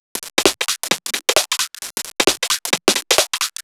Index of /musicradar/uk-garage-samples/132bpm Lines n Loops/Beats
GA_BeatDCrush132-06.wav